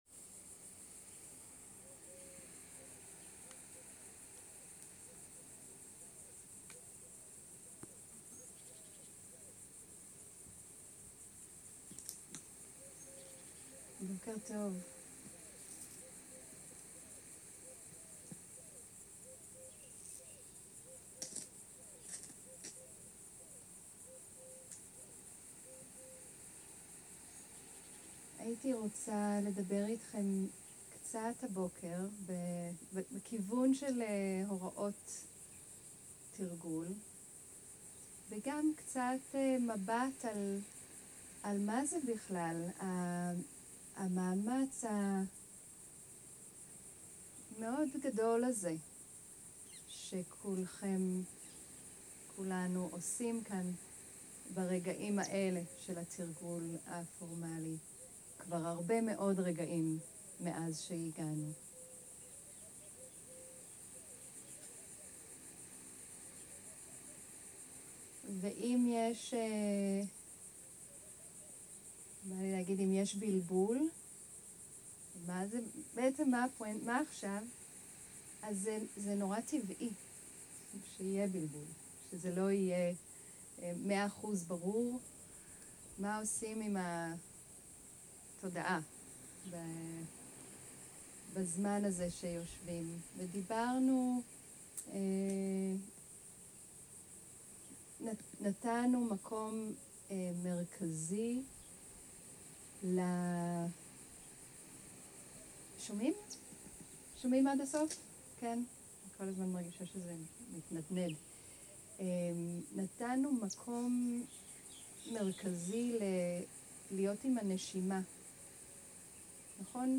סוג ההקלטה: שיחת הנחיות למדיטציה